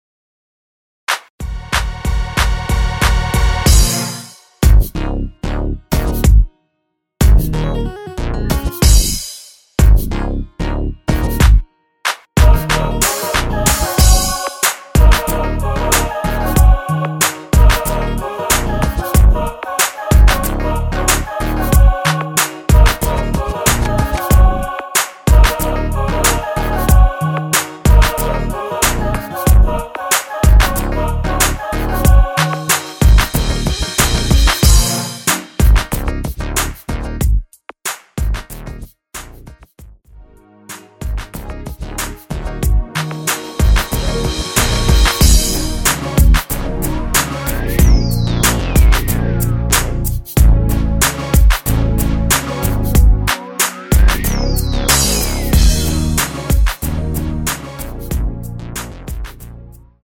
Ebm
앞부분30초, 뒷부분30초씩 편집해서 올려 드리고 있습니다.
중간에 음이 끈어지고 다시 나오는 이유는